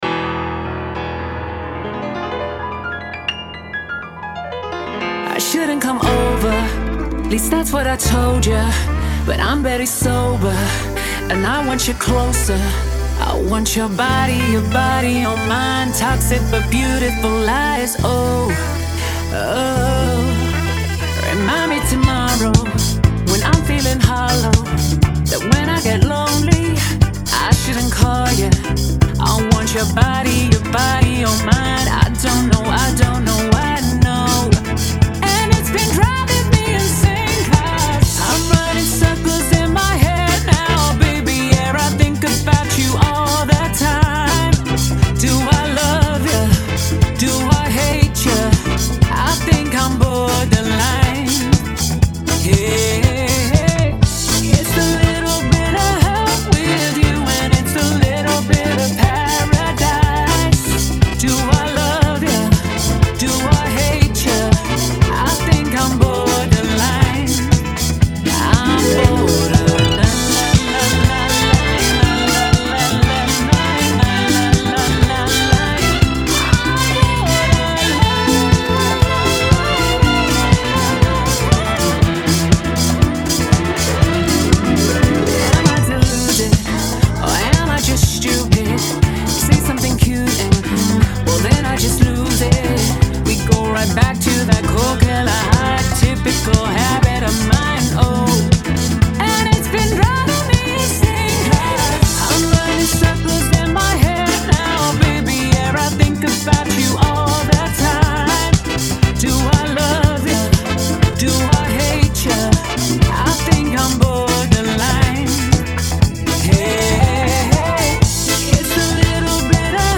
tijdloze disco-klassieker